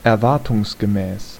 Ääntäminen
IPA : /ɪksˈpɛktɪd/